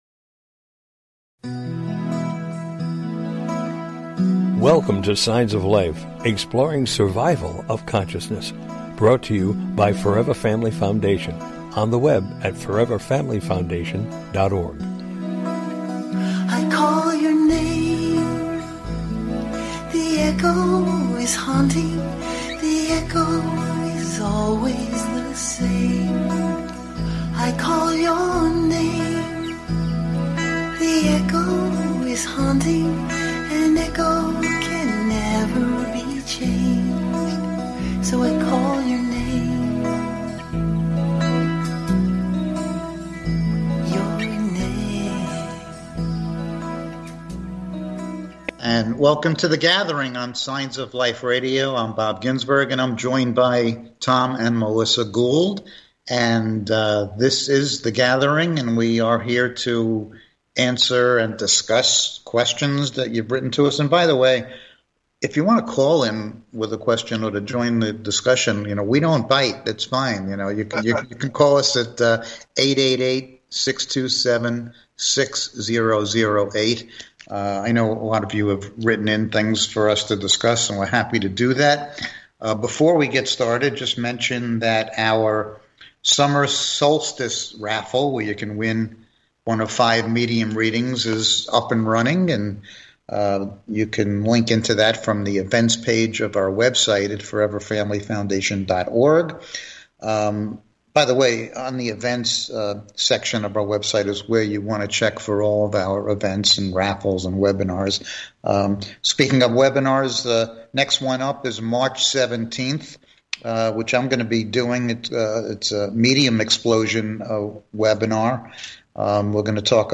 The Gathering: A Discussion about After Life Communication
Signs of Life Radio Show is a unique radio show dedicated to the exploration of Life After Death!